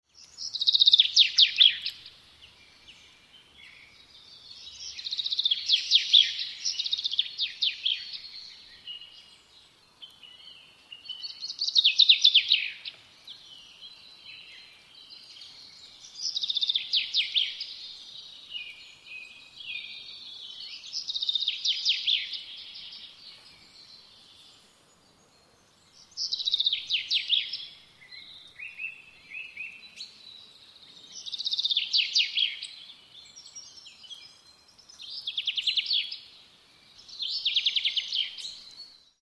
جلوه های صوتی
دانلود آهنگ آواز سهره از افکت صوتی انسان و موجودات زنده
دانلود صدای آواز سهره از ساعد نیوز با لینک مستقیم و کیفیت بالا